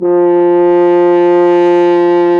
BRS F HRN 0B.wav